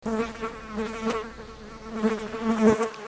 flies.mp3